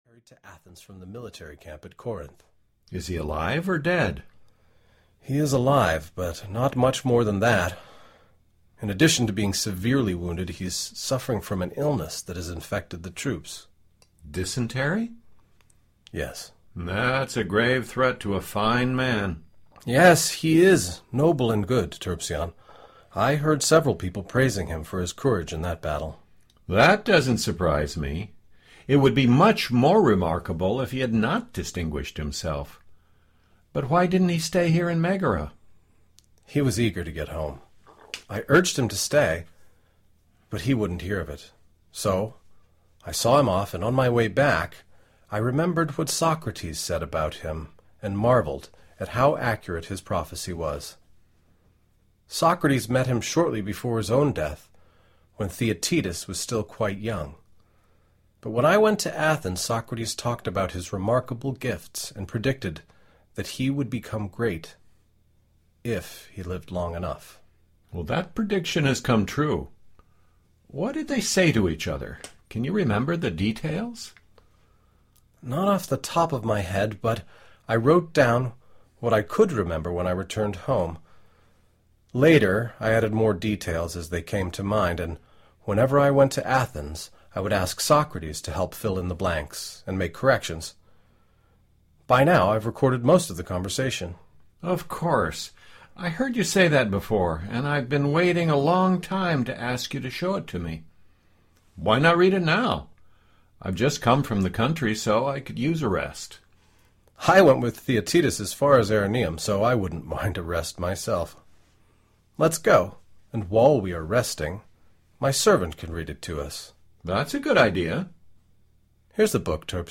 Plato’s Theaetetus (EN) audiokniha
Ukázka z knihy